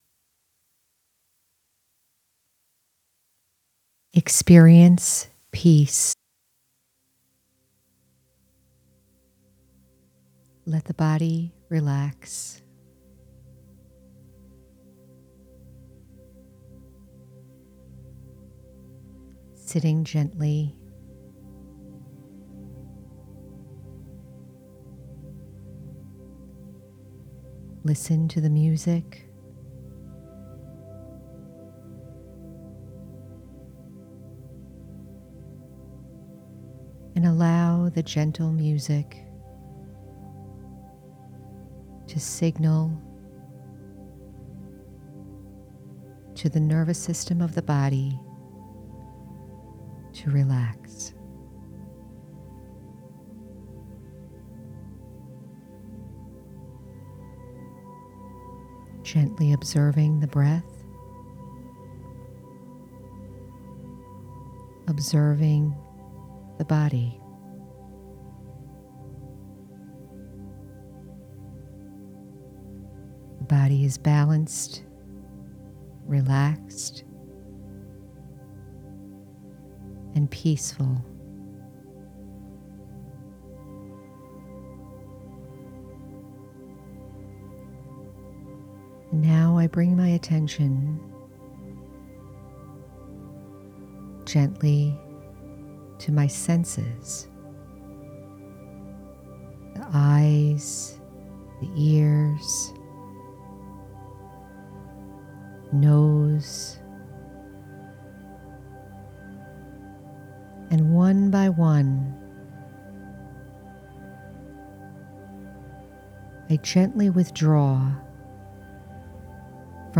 Experience Peace with this gentle guided meditation and soft music.